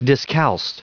Prononciation du mot discalced en anglais (fichier audio)
Prononciation du mot : discalced